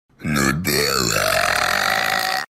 Nutella Meme Effect Sound sound effects free download